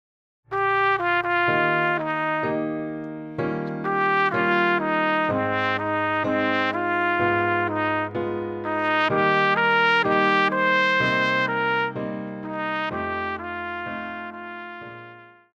Pop
Trumpet
Band
Instrumental
Rock,Country
Only backing